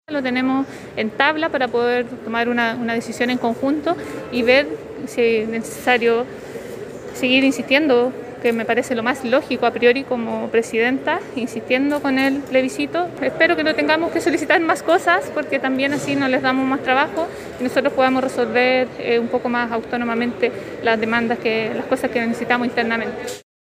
Así lo indicó la presidenta del organismo María Elisa Quinteros, quien en conversación con los micrófonos de La Radio, expresó que a su juicio, es de “toda lógica” insistir más aún a la sede del poder legislativo.